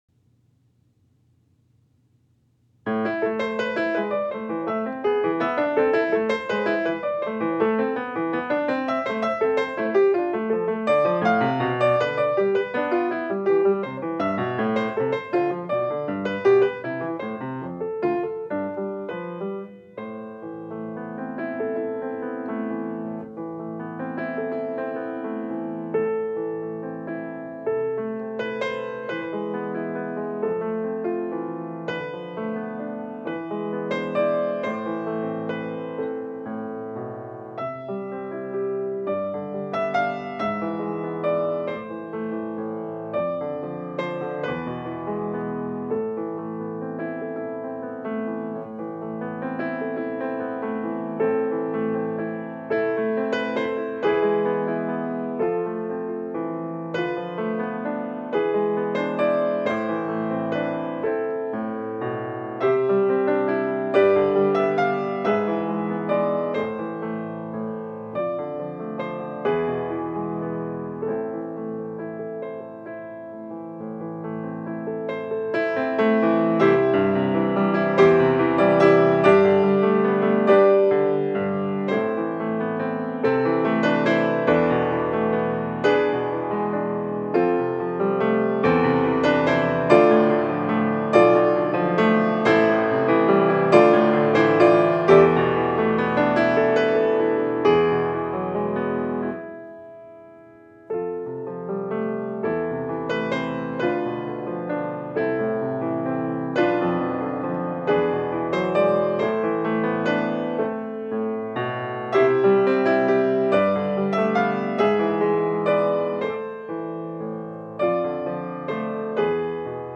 Sanctuary-September-13-audio.mp3